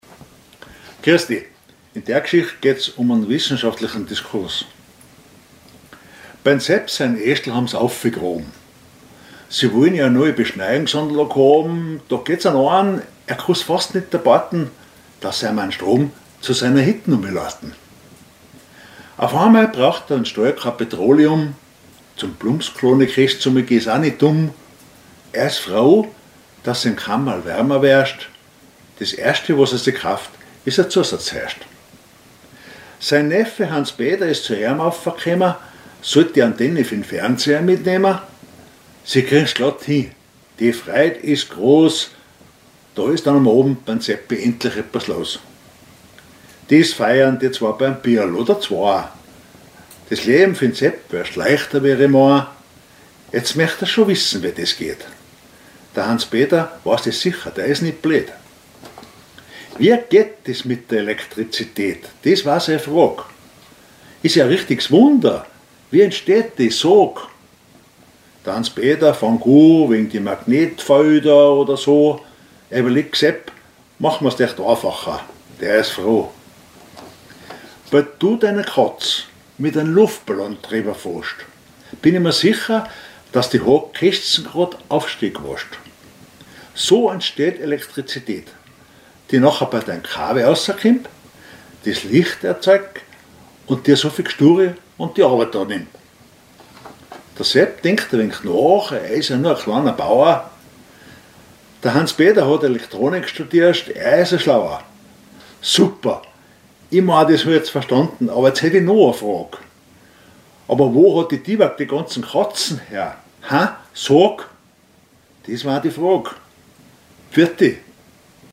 Mundart
Gedicht Monat Mai 2026